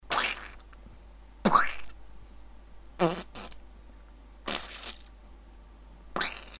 squish
Category 🗣 Voices
fart squash squish voice sound effect free sound royalty free Voices